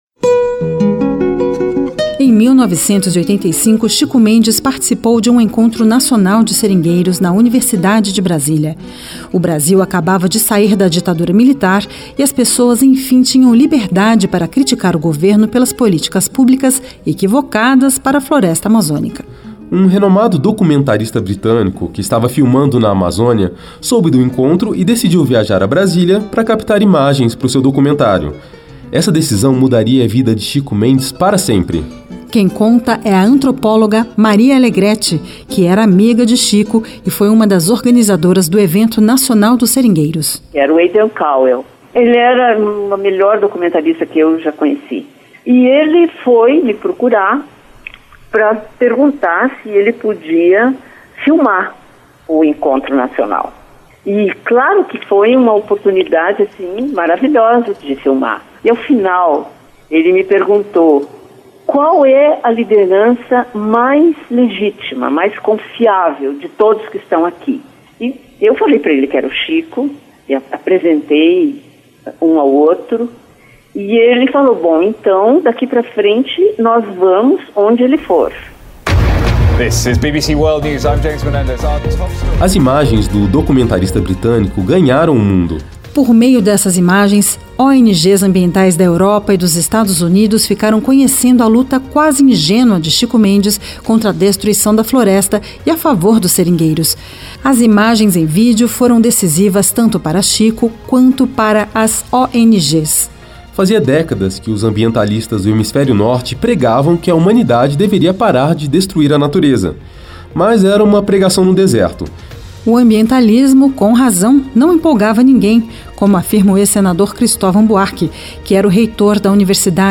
Reportagem da Rádio Senado conta história do seringueiro do Acre e sua luta internacional contra destruição da Amazônia